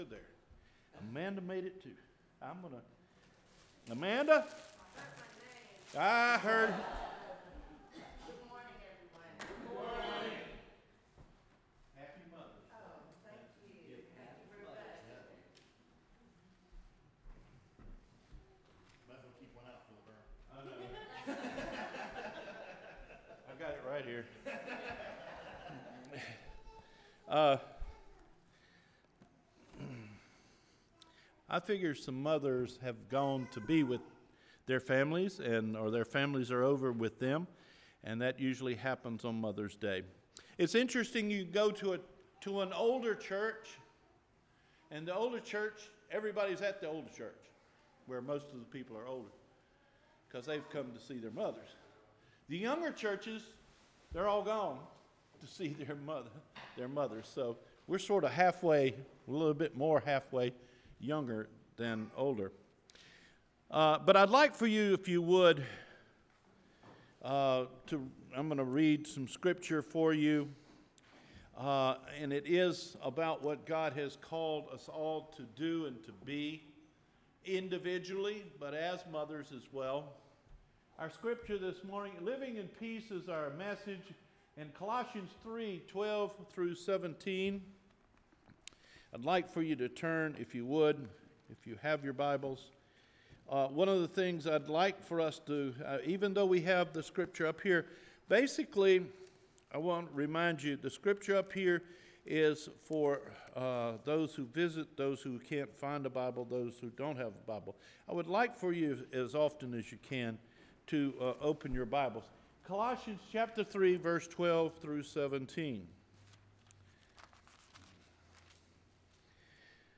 LIVING IN PEACE – MAY 12 SERMON